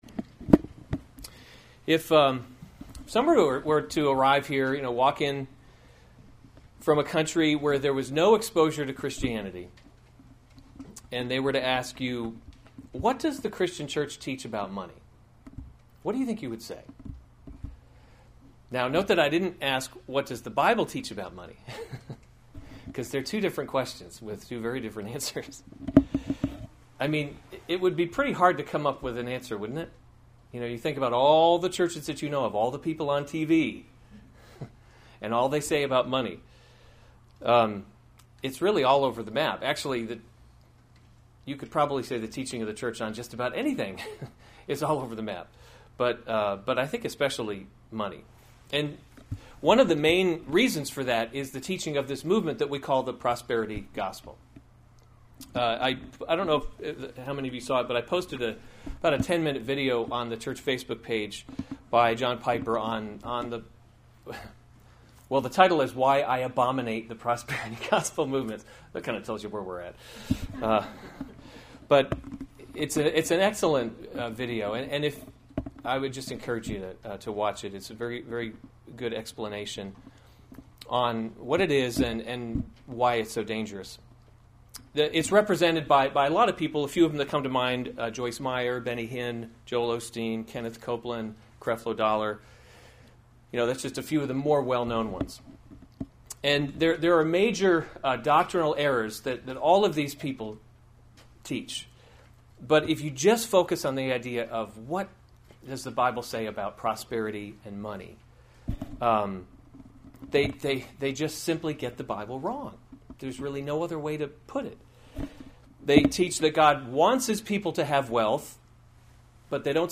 July 2, 2016 Psalms – Summer Series series Weekly Sunday Service Save/Download this sermon Psalm 1 Other sermons from Psalm Book One The Way of the Righteous and the Wicked […]